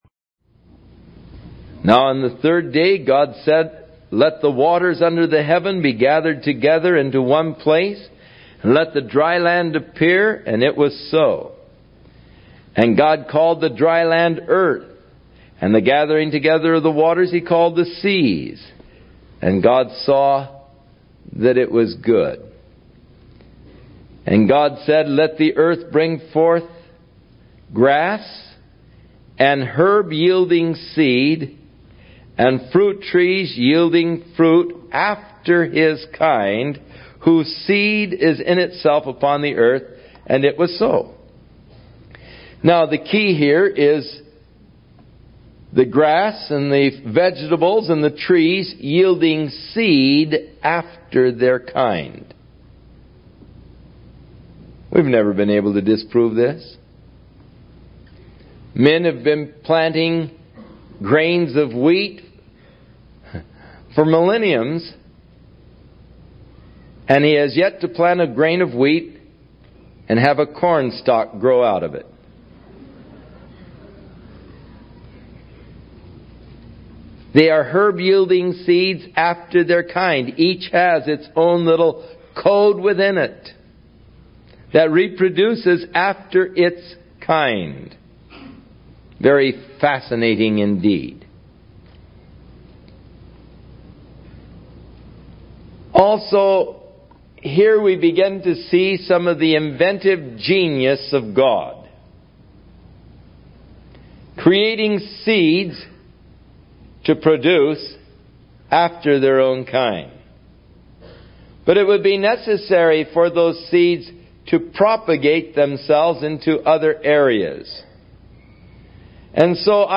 Pastor Chuck Smith, Bible Commentary ⚙